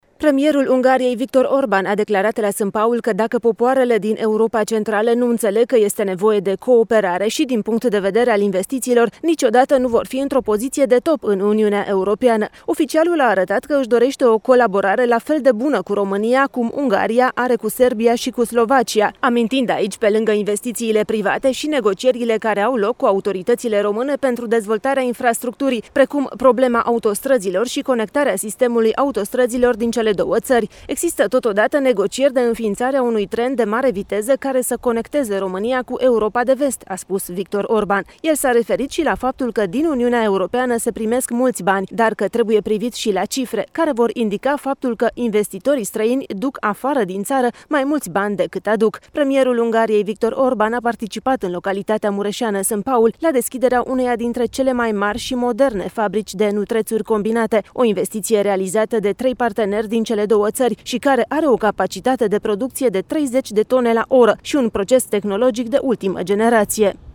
Premierul Ungariei, Viktor Orban, a declarat azi, la Sânpaul, la inaugurarea unei investiţii de aproape 16 milioane de euro că își dorește cu România o cooperare excepţională în planul investițiilor precum cea cu Serbia şi Slovacia.